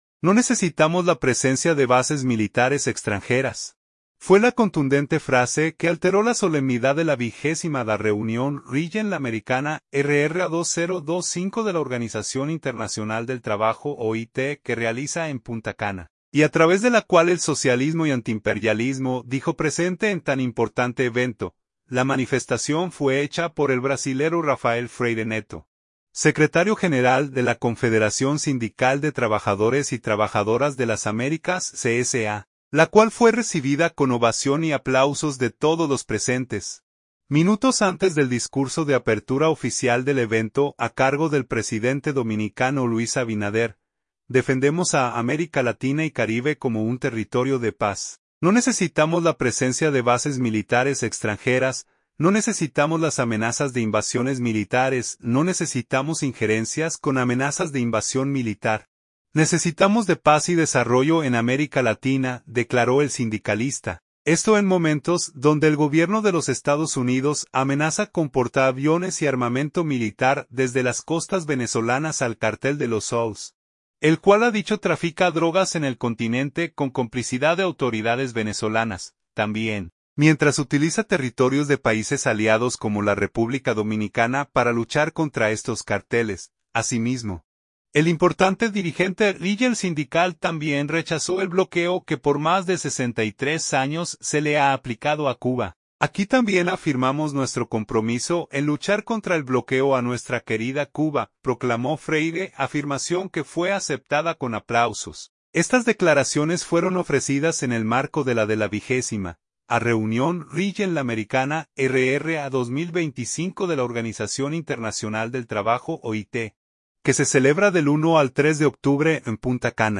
Estas declaraciones fueron ofrecidas en el marco de la de la 20.ª Reunión Regional Americana (RRA 2025) de la Organización Internacional del Trabajo (OIT), que se celebra del 1 al 3 de octubre en Punta Cana.